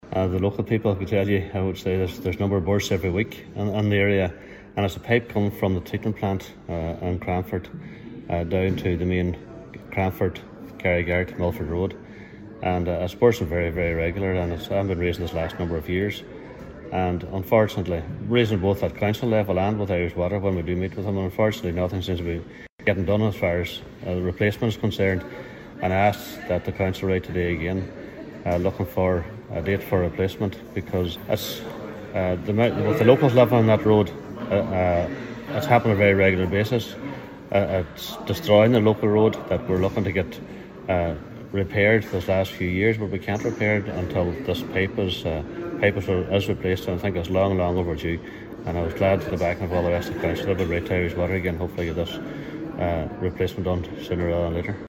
Councillor Liam Blaney, Cathaoirleach of Donegal County Council says works are long overdue: